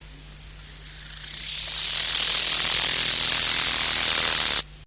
Акустические сигналы саранчового
Акустические сигналы: одиночный самец, Россия, Тува, Овюрский район, окрестности Хандагайты, запись
Температура записи 28-30° С.